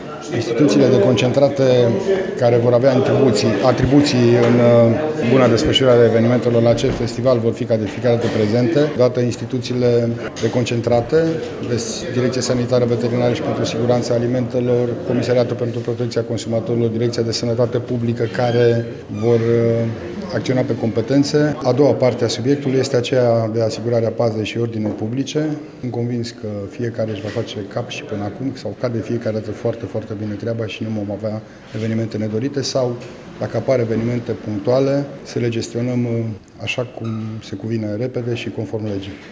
Prefectul județului Mureș, Lucian Goga: